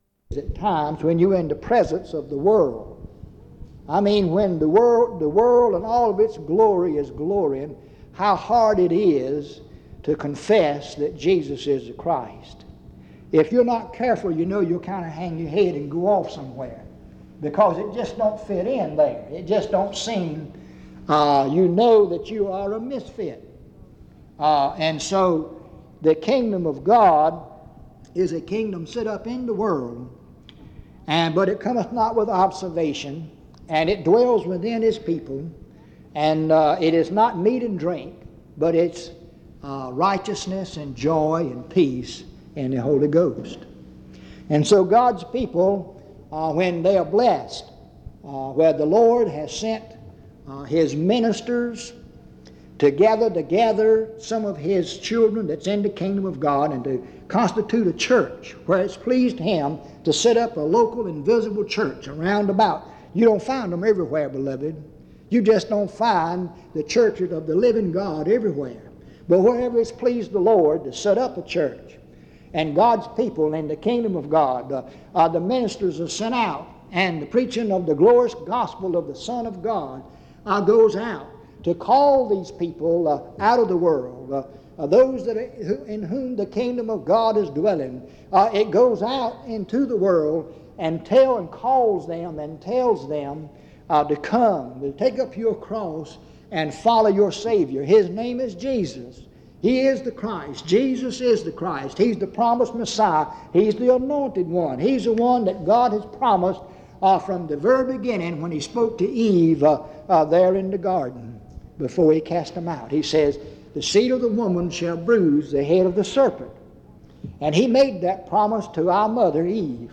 In Collection: Reidsville/Lindsey Street Primitive Baptist Church audio recordings Thumbnail Titolo Data caricata Visibilità Azioni PBHLA-ACC.001_001-A-01.wav 2026-02-12 Scaricare PBHLA-ACC.001_001-B-01.wav 2026-02-12 Scaricare